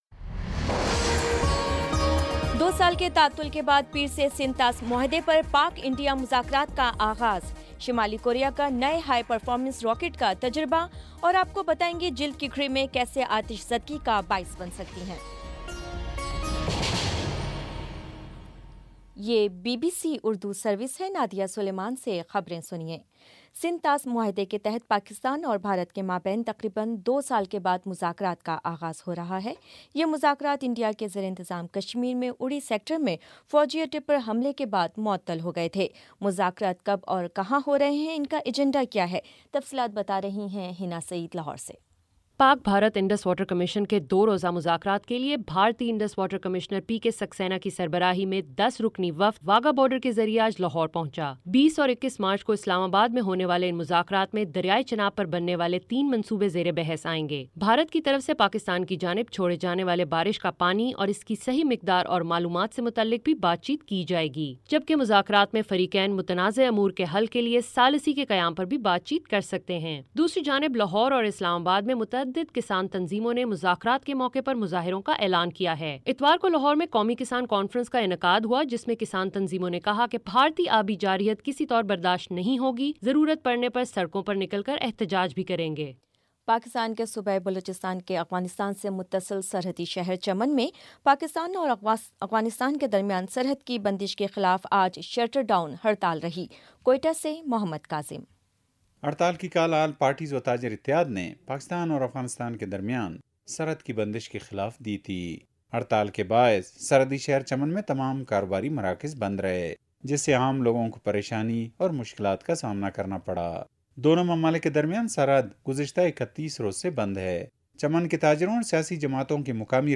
مارچ 19 : شام پانچ بجے کا نیوز بُلیٹن